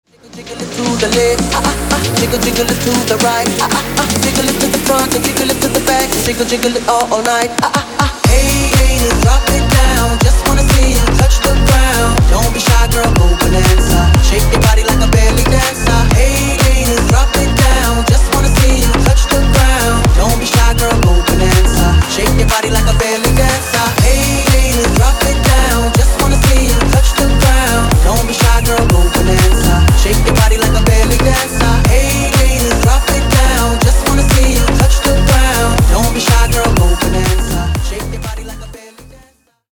Ремикс # Поп Музыка
ритмичные